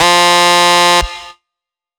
Index of /99Sounds Music Loops/Instrument Oneshots/Leads